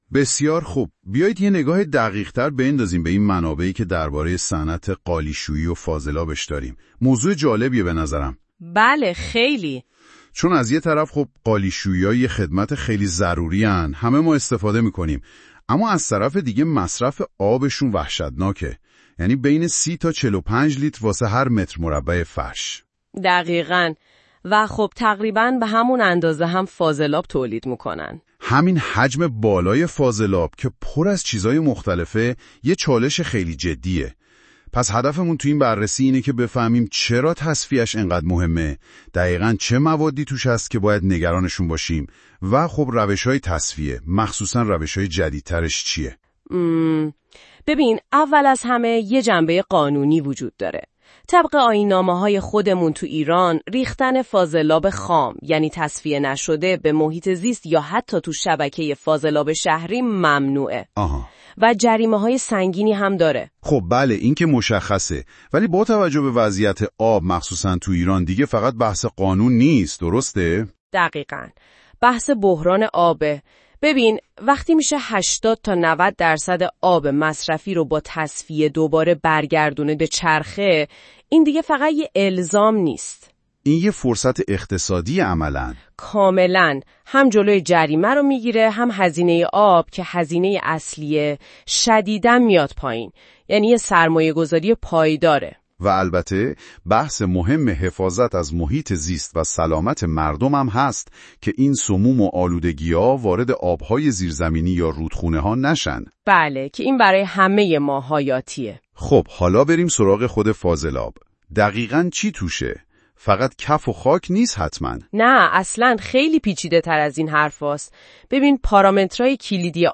اگر فرصت خواندن این مقاله را ندارید به راحتی با پخش فایل صوتی زیر محتوای این مقاله را در قالب یک مکالمه جذاب بشنوید.